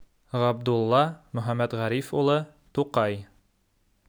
Summary Description Ğabdulla Tuqay.wav English: Pronunciation of Ğabdulla Tuqay (Габдулла Тукай), Möxəmmətğərif ulı.